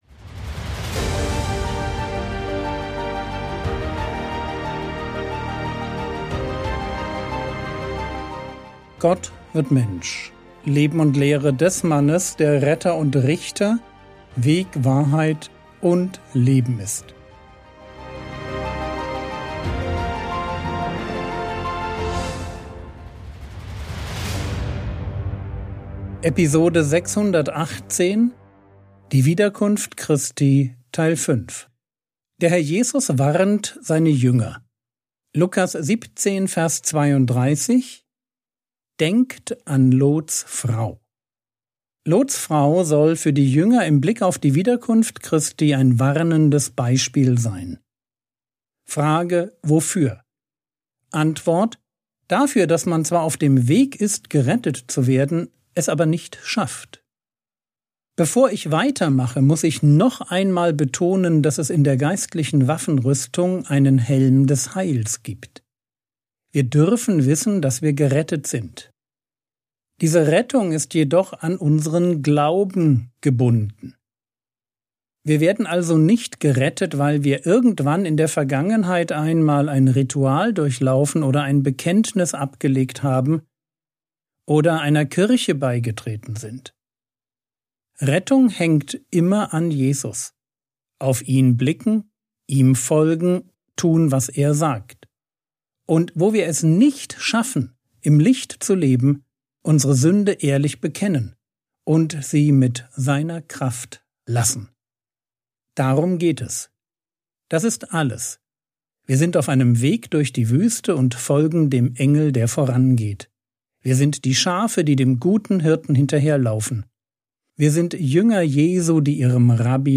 Episode 618 | Jesu Leben und Lehre ~ Frogwords Mini-Predigt Podcast